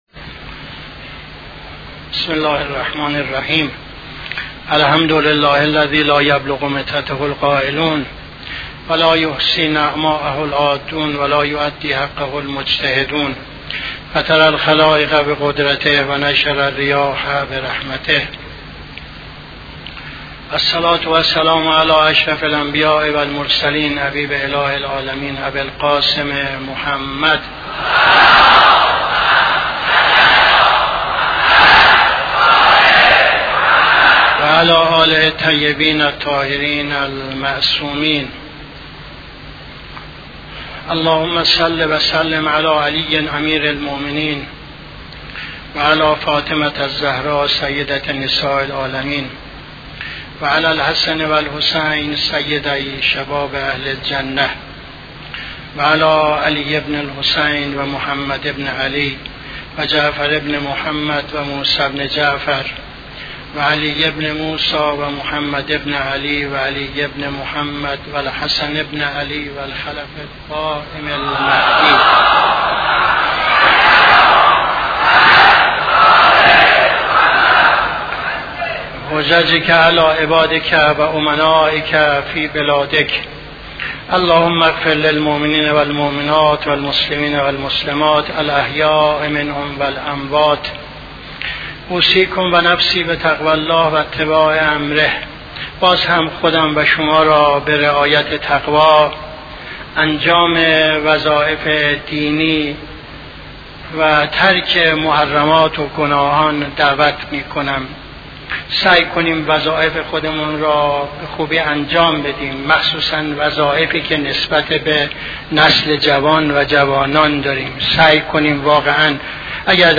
خطبه دوم نماز جمعه 27-01-78